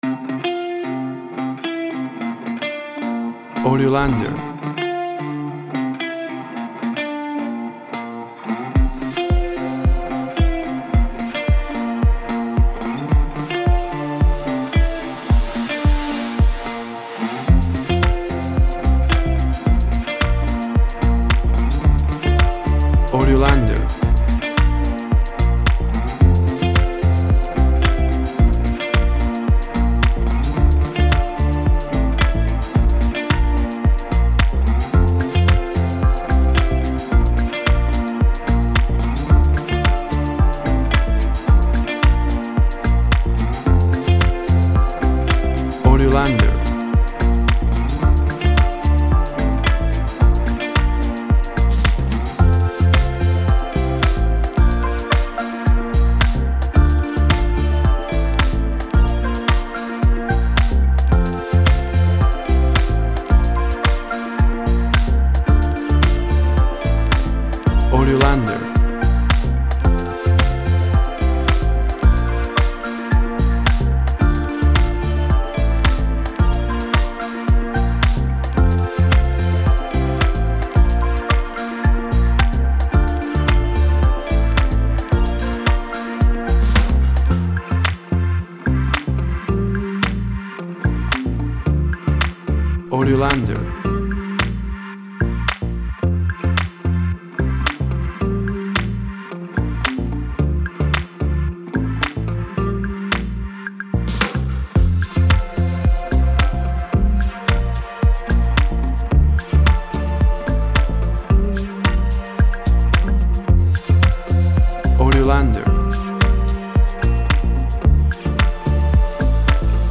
An electro lounge track
Tempo (BPM) 110